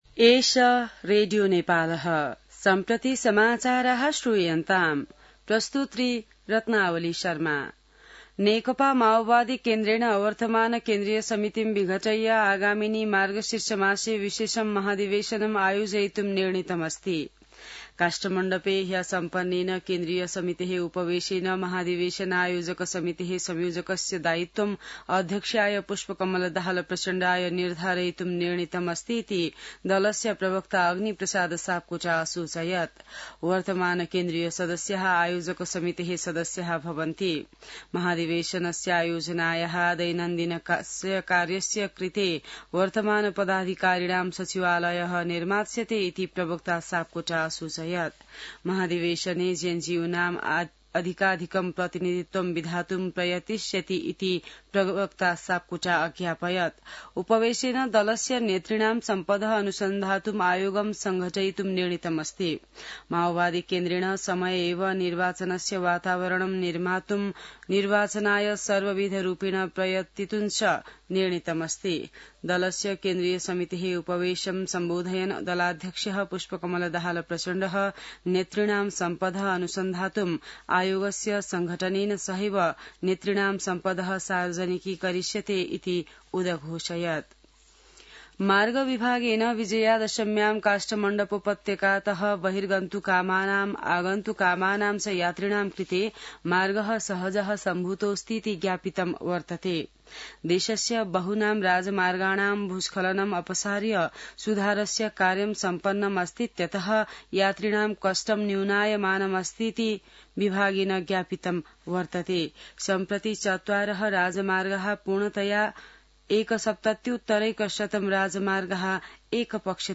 संस्कृत समाचार : ११ असोज , २०८२